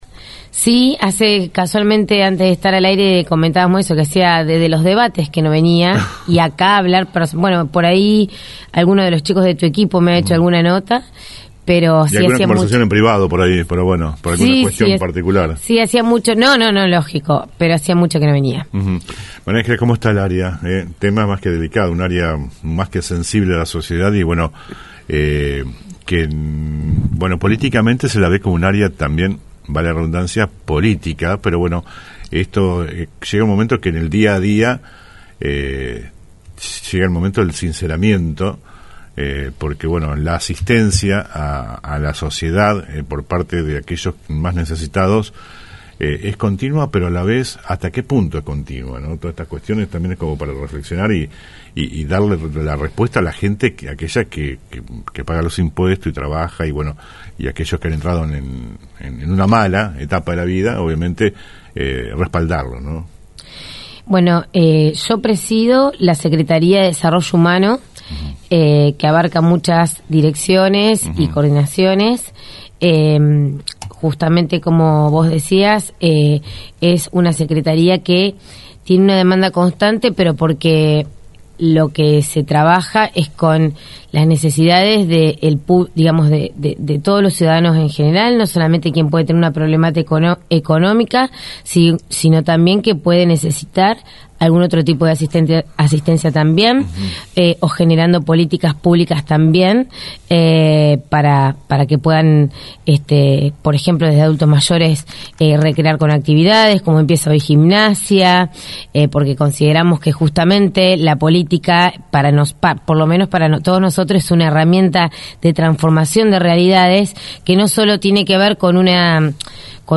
En la mañana de El Periodístico, conversamos con la secretaria del área desarrollo humano y pre candidata a concejal en 2do lugar por el oficialismo.